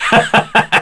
Baudouin-Vox_Skill4-1_kr.wav